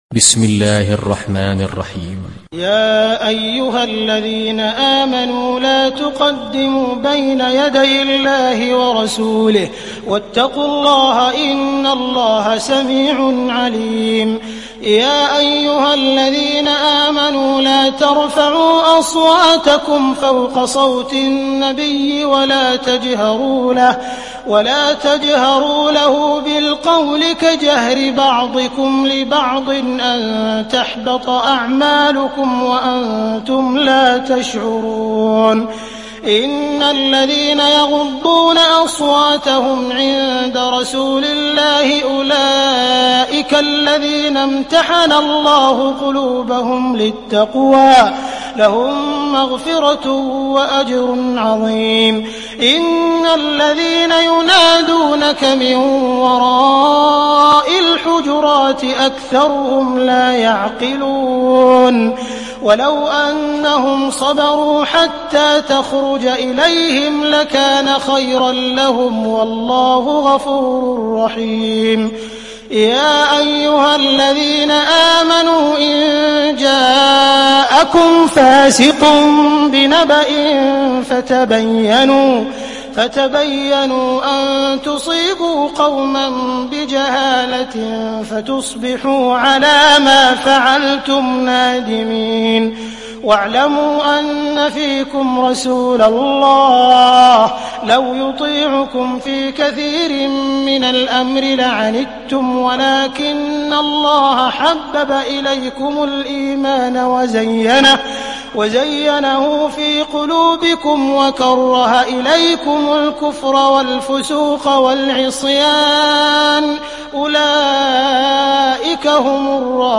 دانلود سوره الحجرات mp3 عبد الرحمن السديس روایت حفص از عاصم, قرآن را دانلود کنید و گوش کن mp3 ، لینک مستقیم کامل